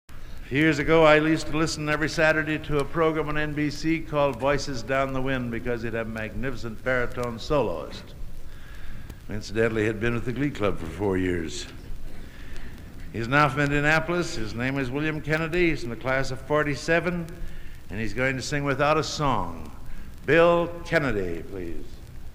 Genre: | Type: Director intros, emceeing